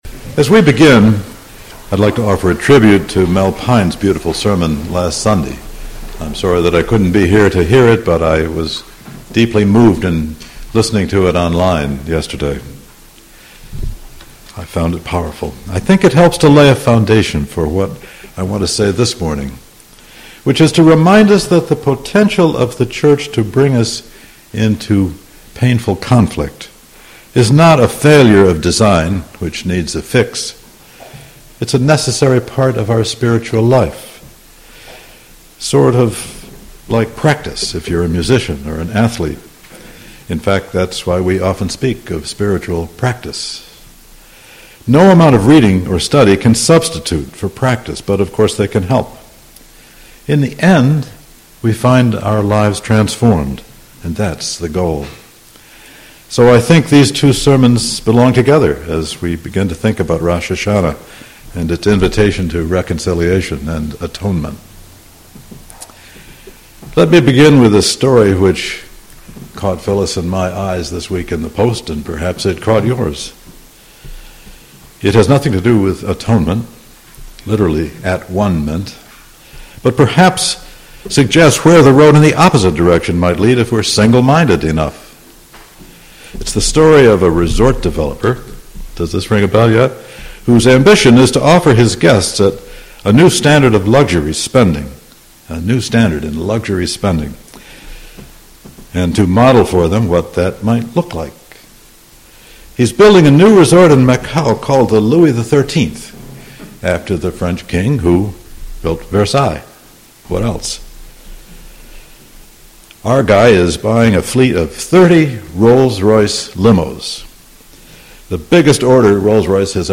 This sermon reflects on a previous sermon about community and encourages the congregation to embrace the church’s potential for conflict as a necessary part of spiritual growth.